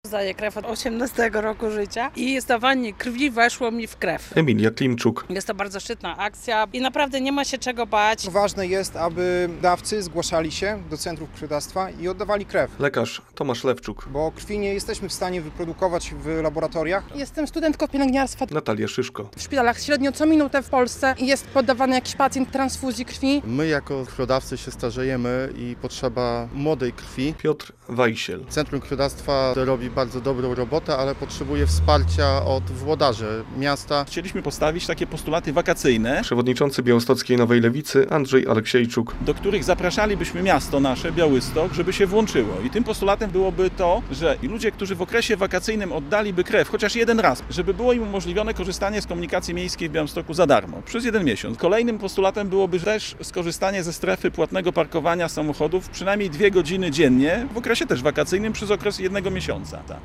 Przedstawiciele białostockiej Nowej Lewicy zachęcają do oddawania krwi - relacja